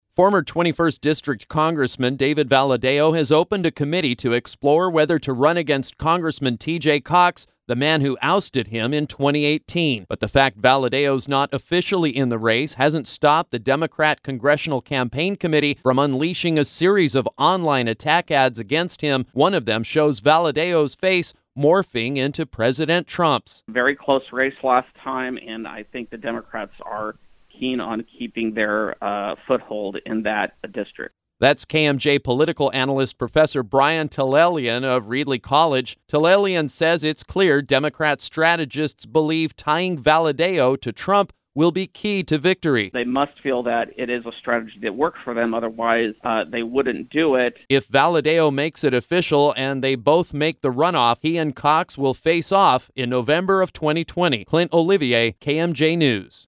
DCCC online attack ad.